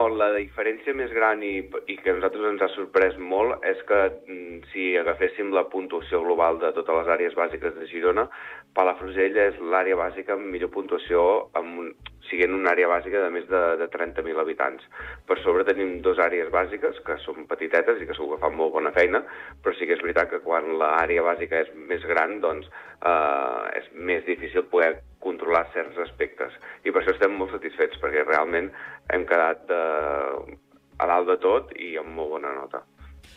EntrevistesProgramesSupermatí